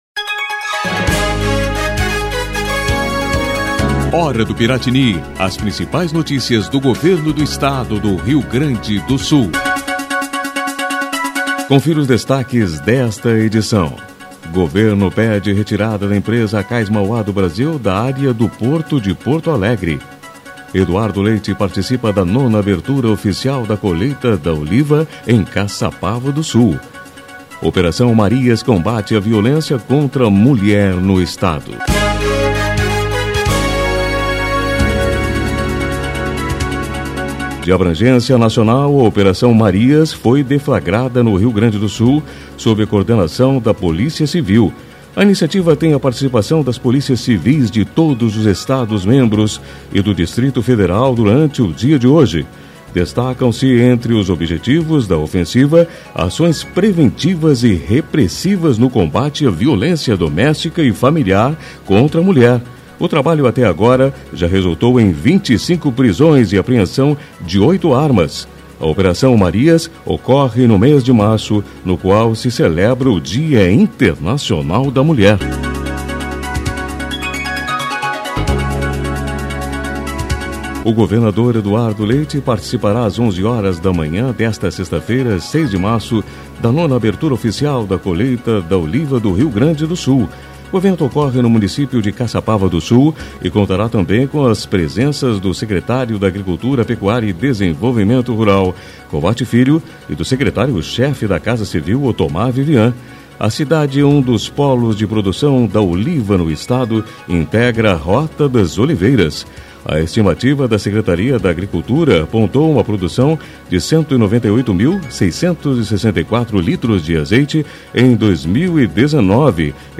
A Hora do Piratini é uma síntese de notícias do Governo do Estado, produzida pela Secretaria de Comunicação.